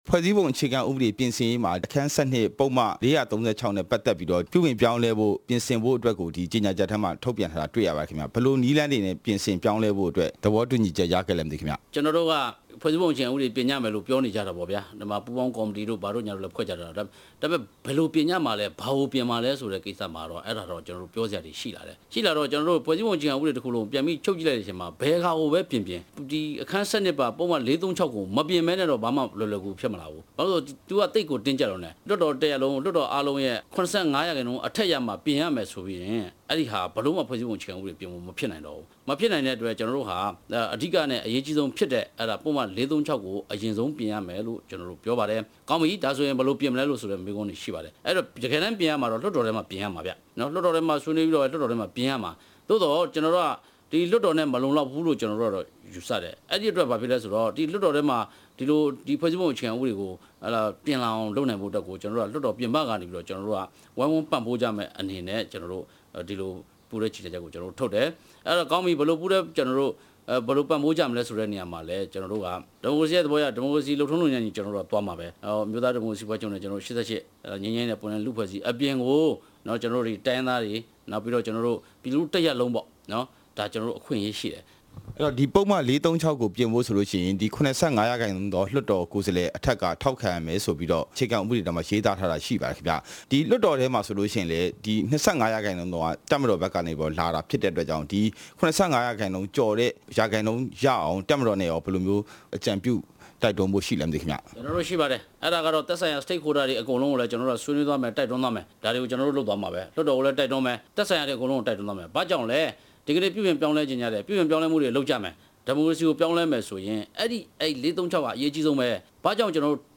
ကိုဂျင်မီနဲ့ မေးမြန်းချက်